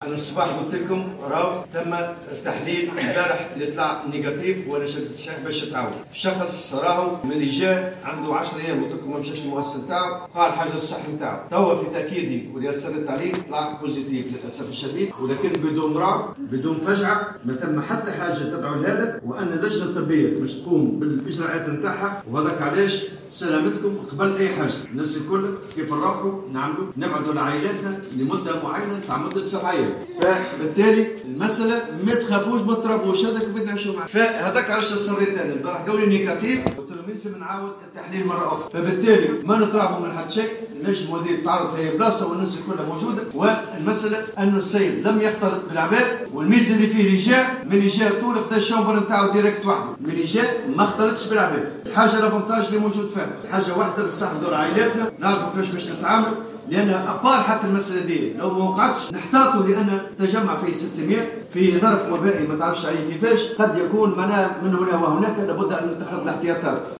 وأضاف خلال كلمة على هامش أشغال المجلس الوطني للمنظّمة الشّغيلة في الحمامات أنه طالب من الجهات المعنية بإجراء تحليل ثان للنقابي الذي يشارك في أشغال المجلس ليتم الكشف لاحقا عن اصابته بفيروس كورونا.